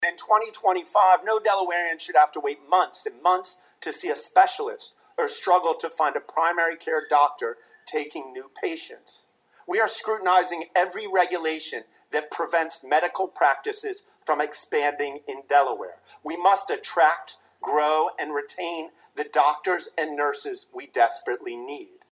Governor Meyer Delivers State of the State Address